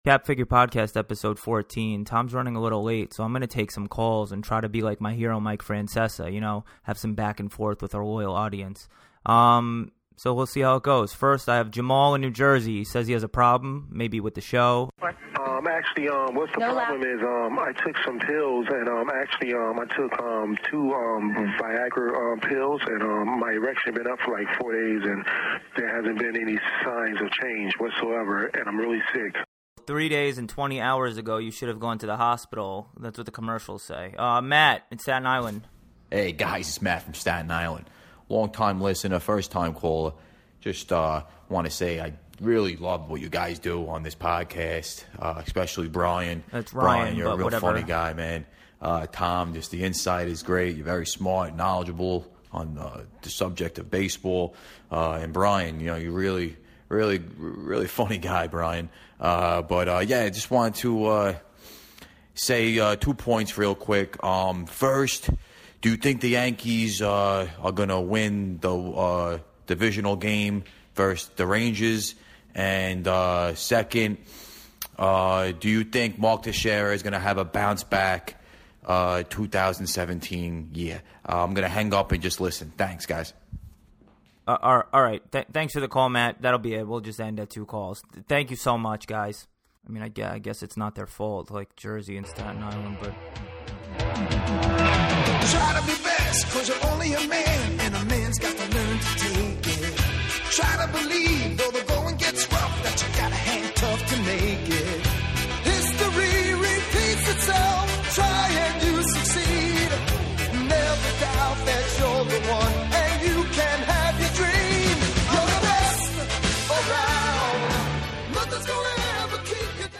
After hearing from the show’s first live callers, the duo go on to discuss the merits of relief pitching and the intricacies of playoff baseball.